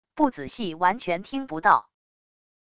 不仔细完全听不到wav音频